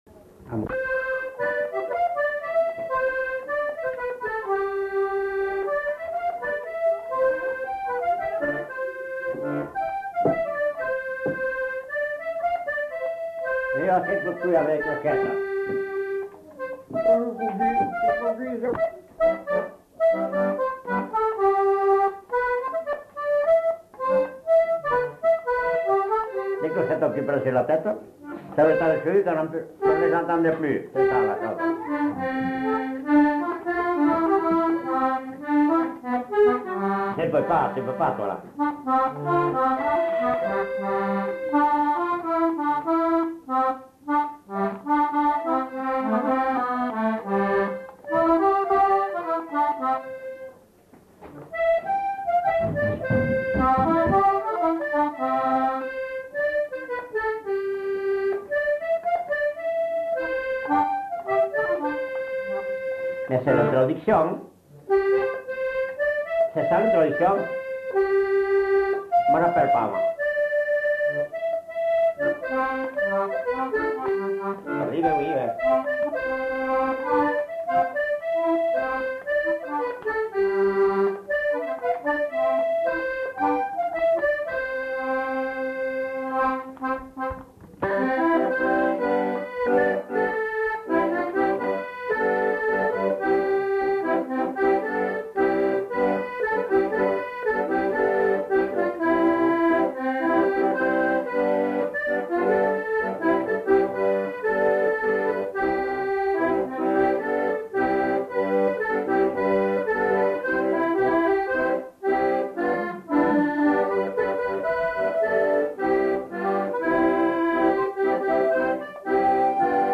interprété à l'accordéon diatonique
enquêtes sonores